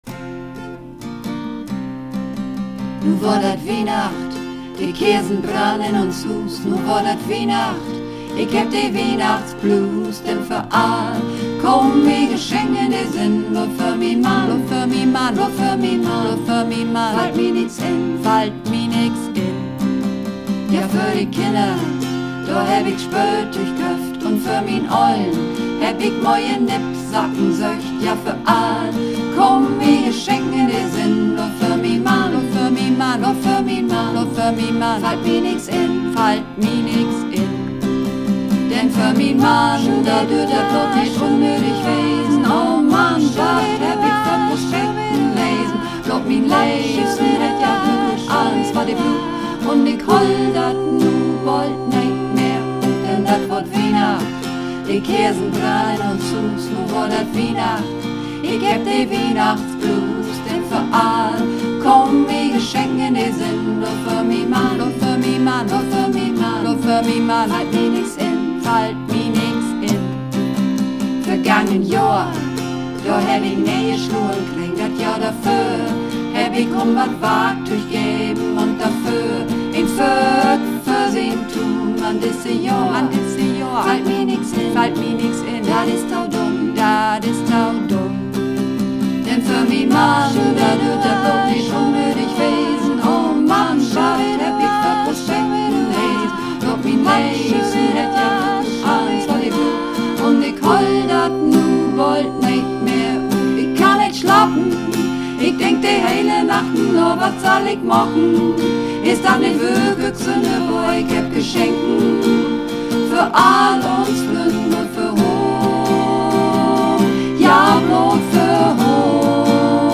Übungsaufnahmen - Wiehnachtsblues
Wiehnachtsblues__4_Mehrstimmig.mp3